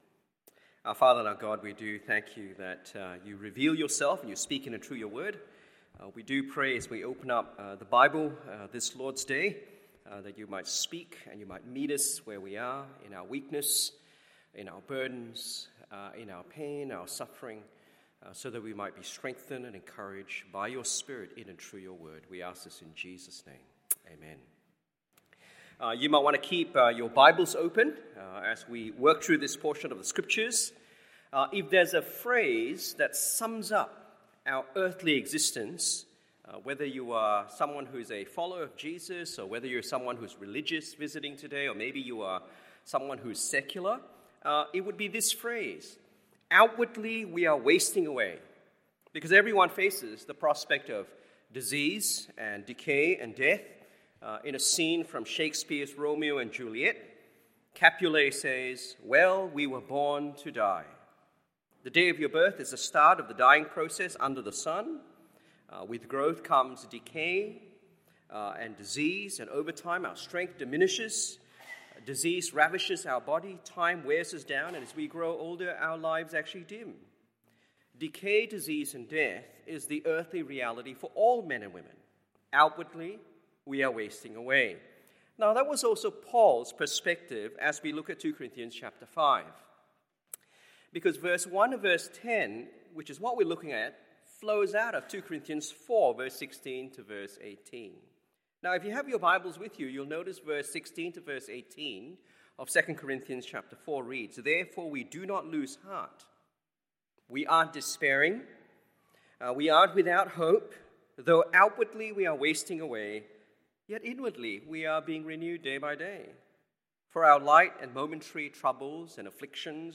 Morning Service 2 Corinthians 4:16-5:10 1. Our Future Hope in Death 2. Our Present Groaning In Life 3. Our Confidence In Life And Death…